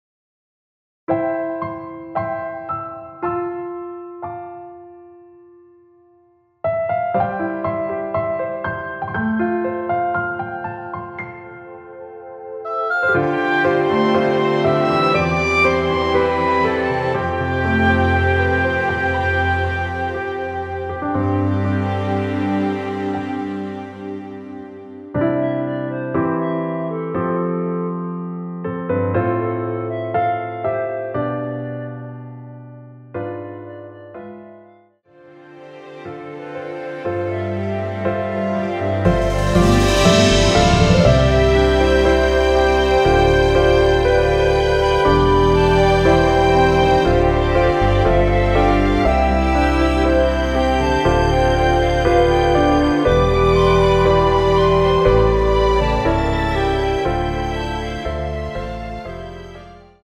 원키에서(+5)올린 멜로디 포함된 MR입니다.(미리듣기 확인)
앞부분30초, 뒷부분30초씩 편집해서 올려 드리고 있습니다.
중간에 음이 끈어지고 다시 나오는 이유는